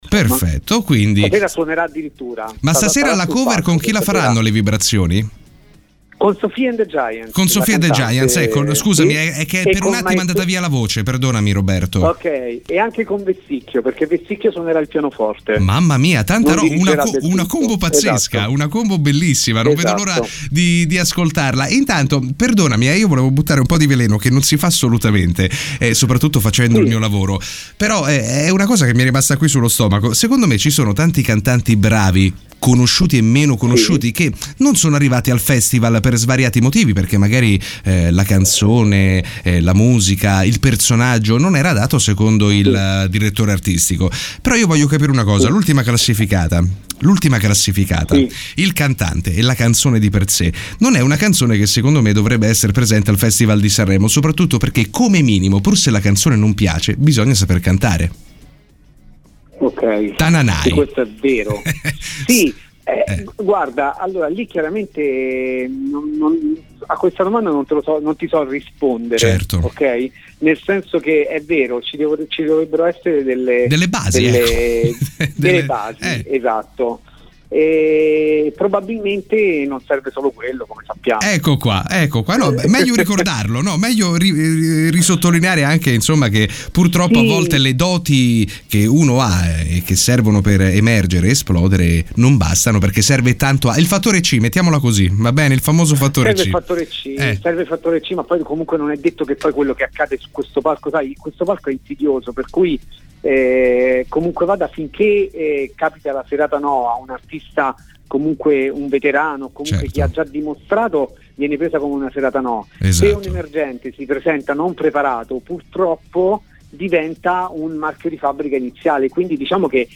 IN RADIO